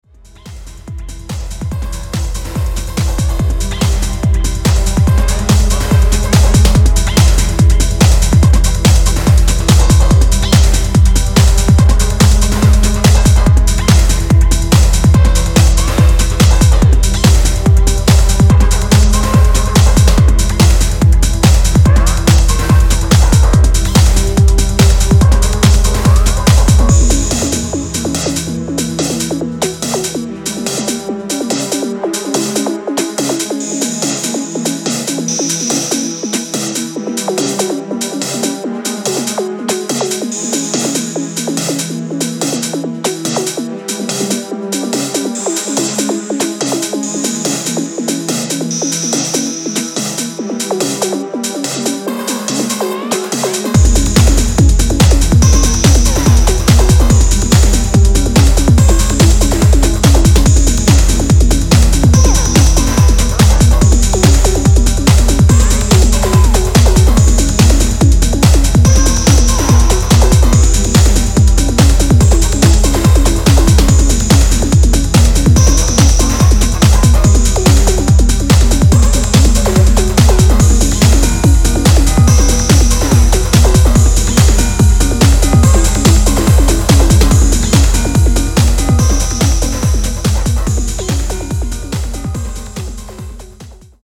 dramatic and eery driving Techno track
Genre Techno